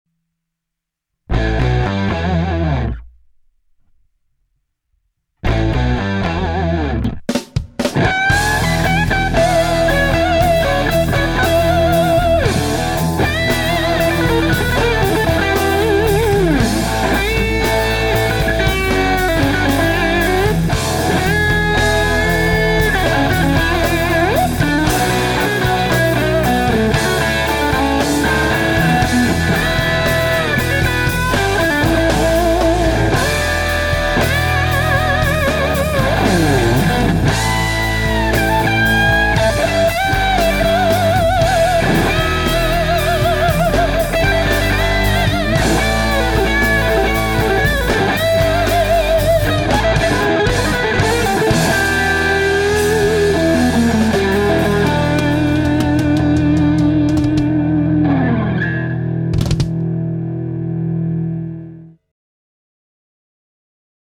playing an Express amp